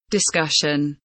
discussion kelimesinin anlamı, resimli anlatımı ve sesli okunuşu